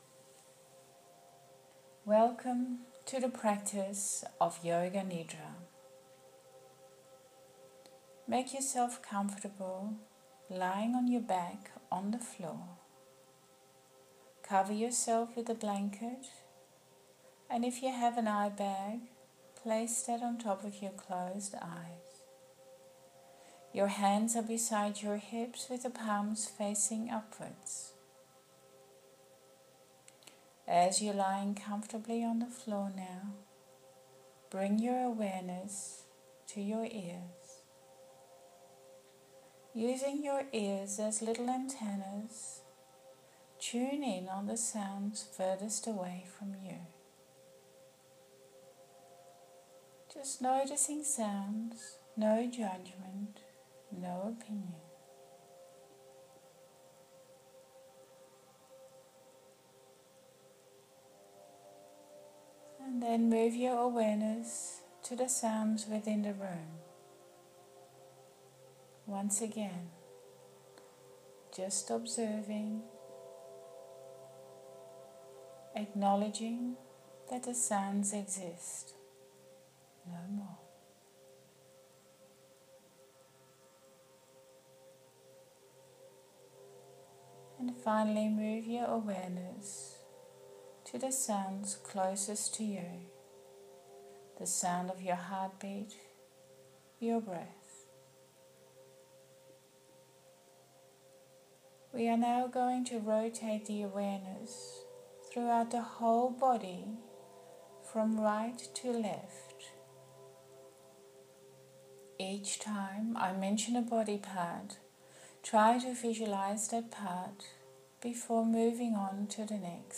It is a deep relaxation practice in which the practitioner follows a guided visualization of the whole body from right to left.